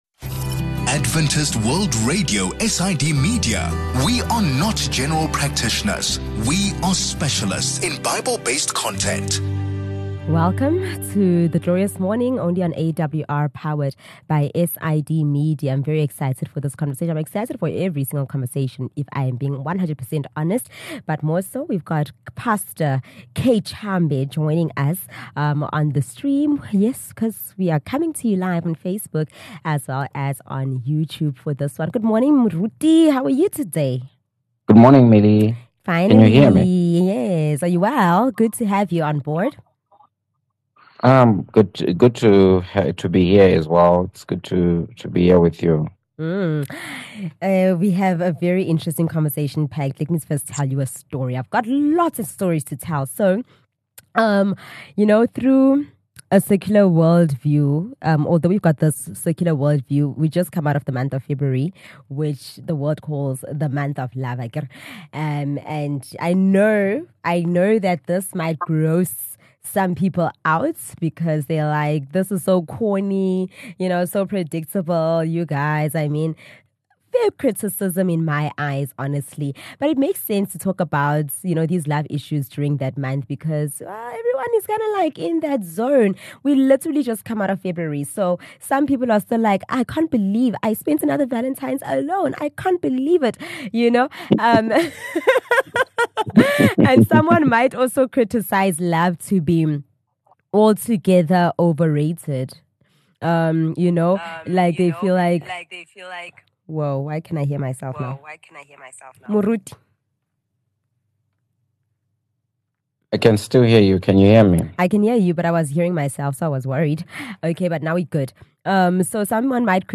One of the most compelling pre-marital counselling sessions you will ever have is this online conversation